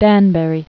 (dănbĕrē, -bə-rē)